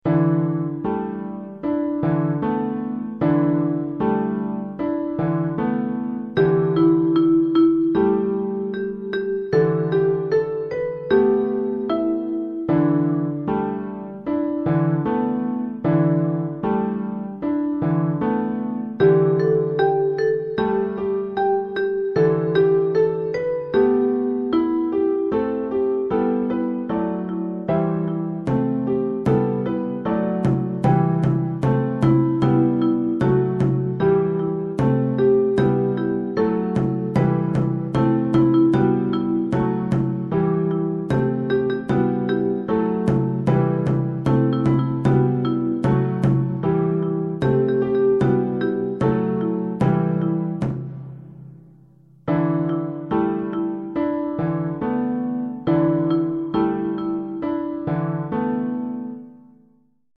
7 haiku per voci e pianoforte